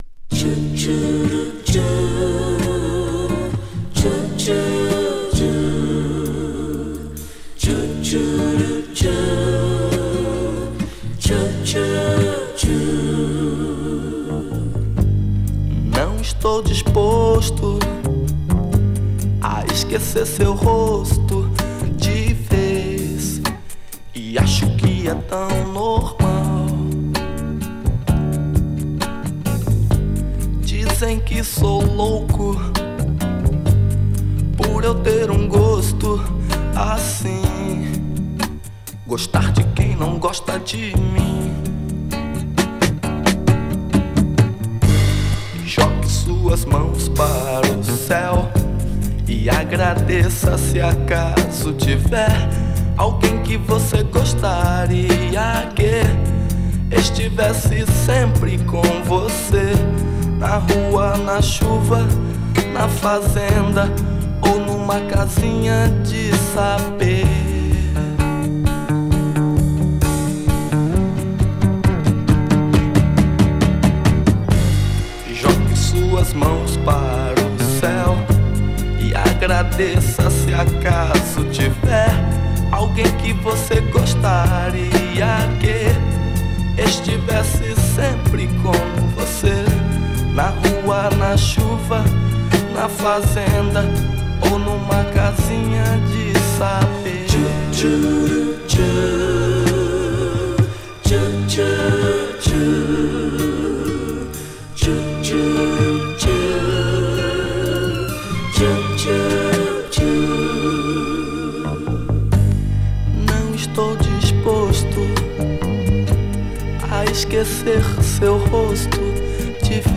ファンク・ソウルからブラジリアン、そしてハウスとサントラとは思えない驚きの完成度!!!